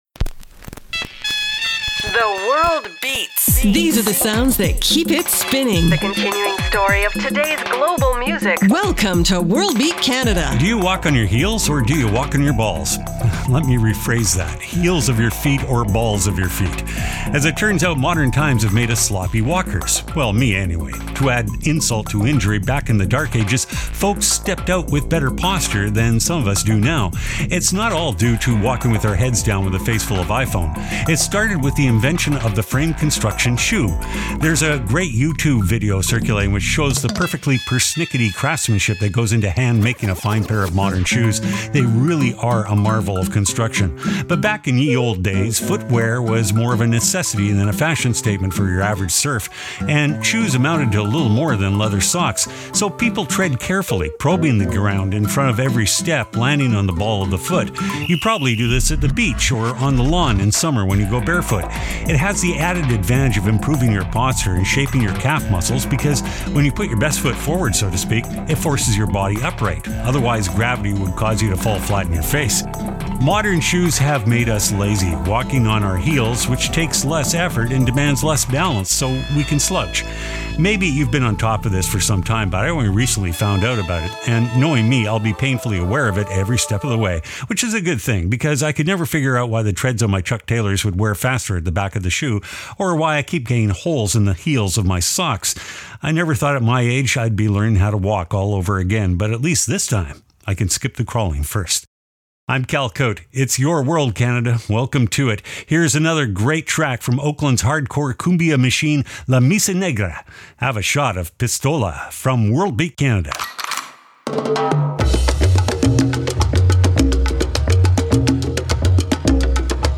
exciting contemporary global music alternative to jukebox radio